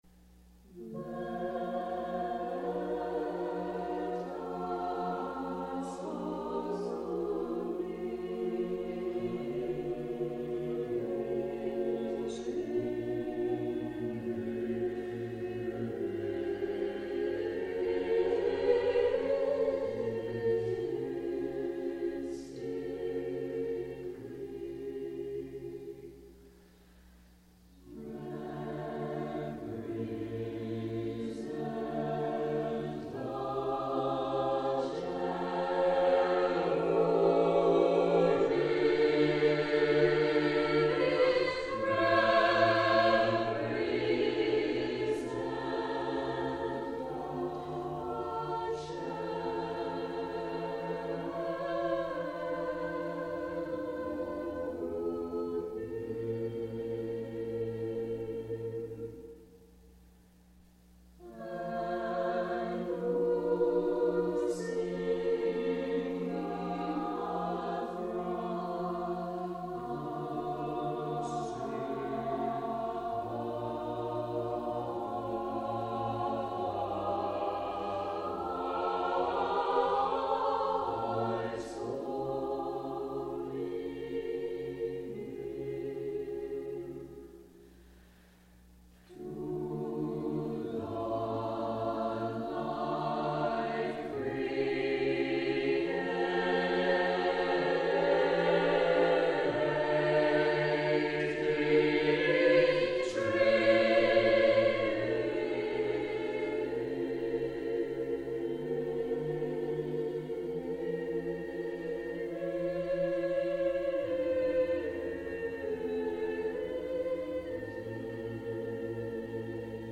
Sacré ; Hymne liturgique (orthodoxe) ; Orthodoxe
solennel ; majestueux ; pieux
SATB (4 voix mixtes )
Tonalité : fa majeur